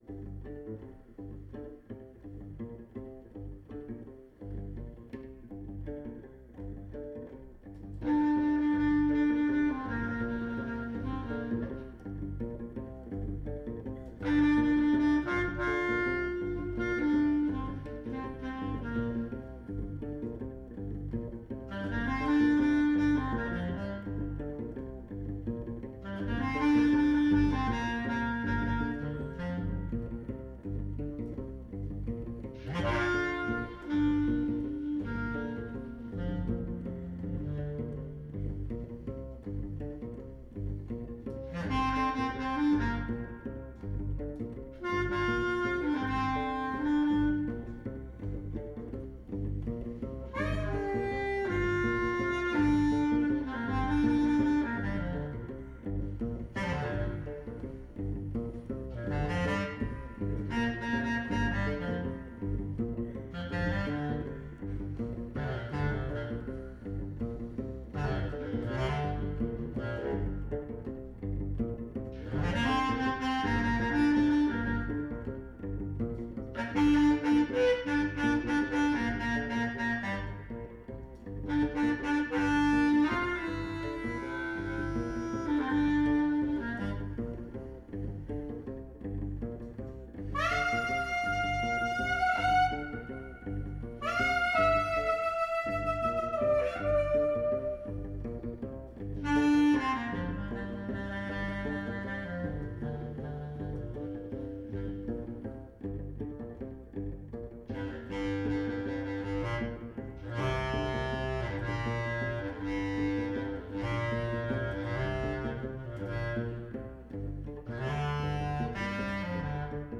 Recorded live at the Maid’s Room, NYC.
Sousaphone
alto sax (1) & bass clarinet (2)
Stereo (Pro Tools)